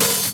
DUS_OHH.wav